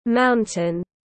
Mountain /ˈmaʊn.tɪn/